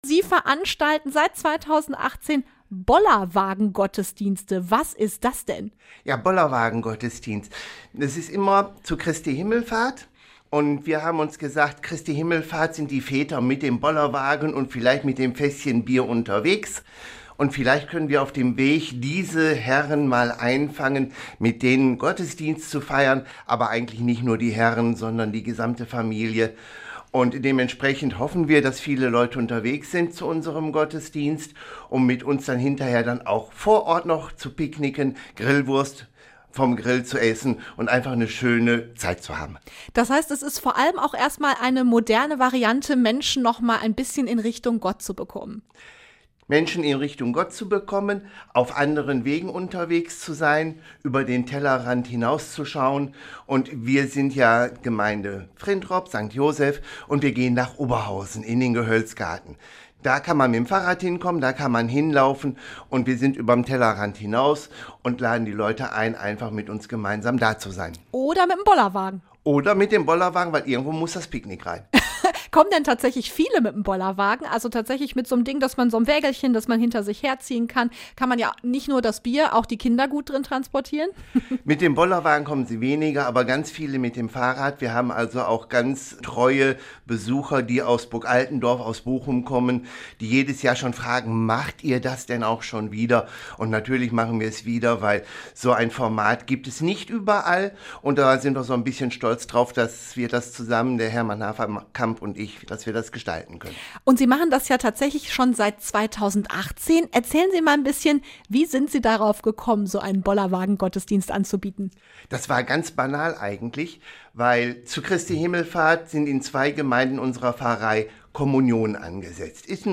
int-bollerwagengottesdienst-fuer-online.mp3